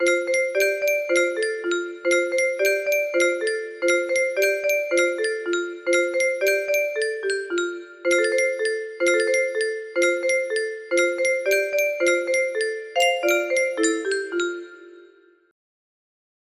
Backe, backe Kuchen music box melody